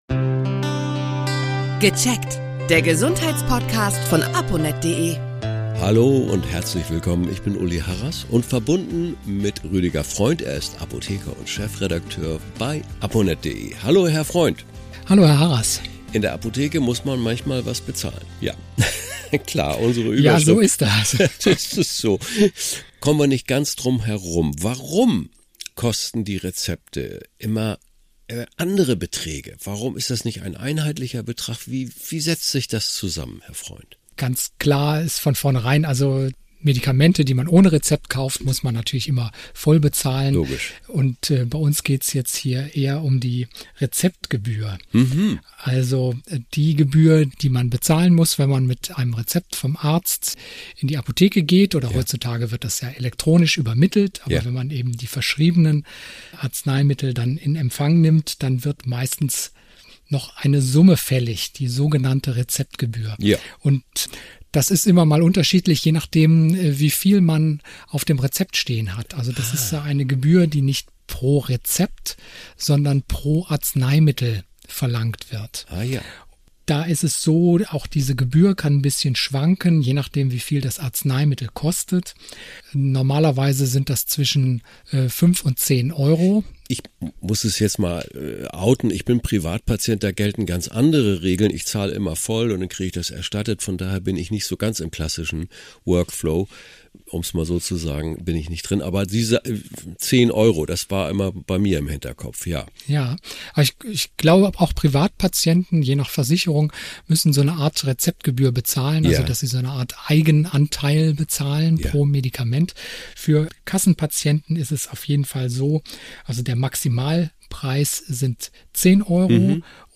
erklärt ein Apotheker im Podcast.